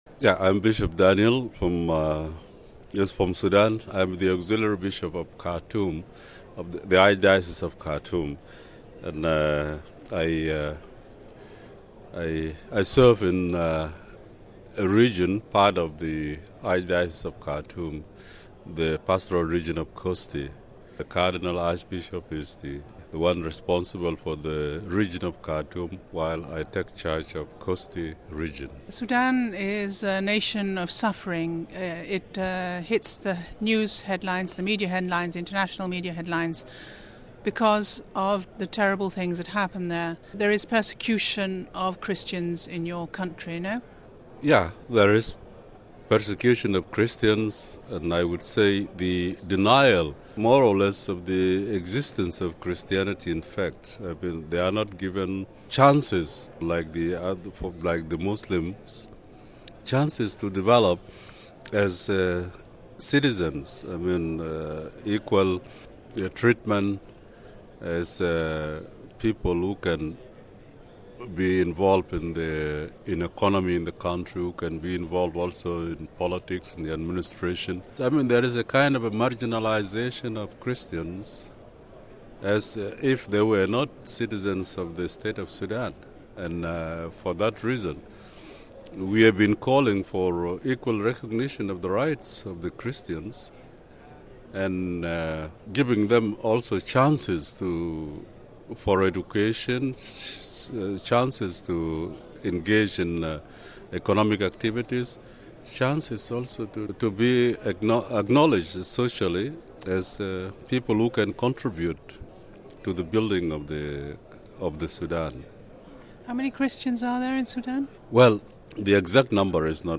The Auxiliary Bishop of Khartoum in Sudan, Daniel Marco Kur Adwok, takes the time to give us an in depth look at the plight of Christians in his nation .